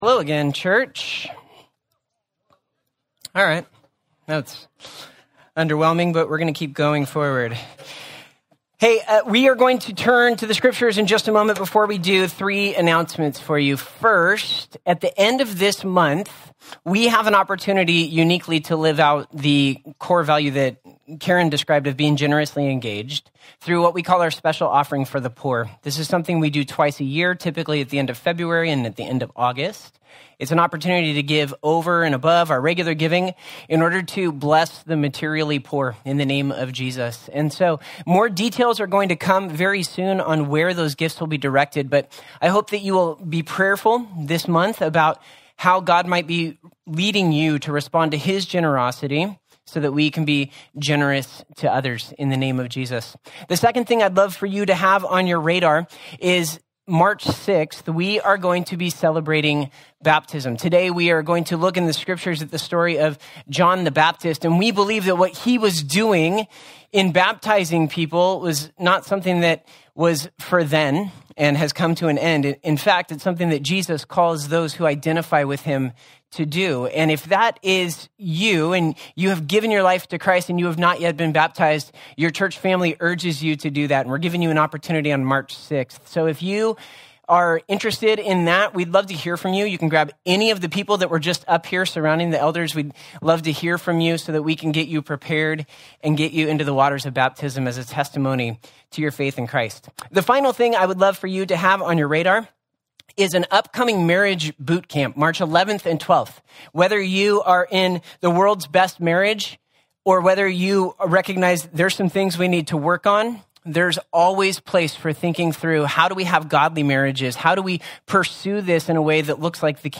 Sermon-.mp3